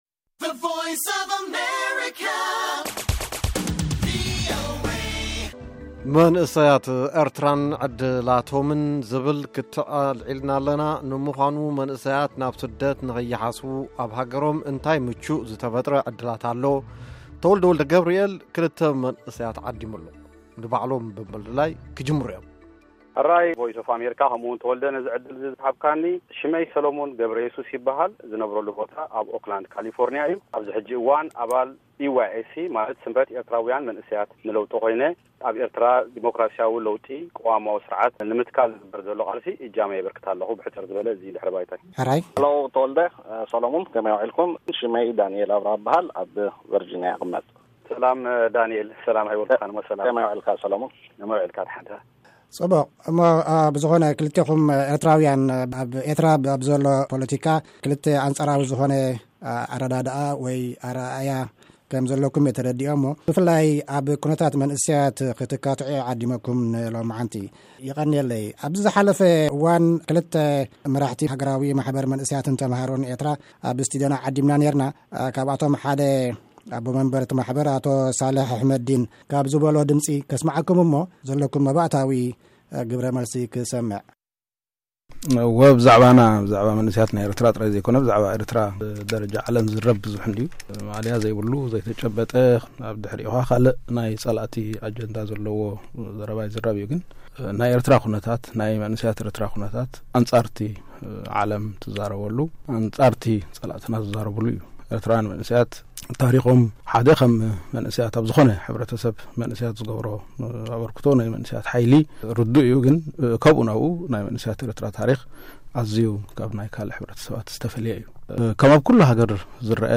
ኽትዕ፡ ክልተ ኤርትራውያን ኣብ ጠንቂ ስደት መንእሰያት(1ይ ክፋል)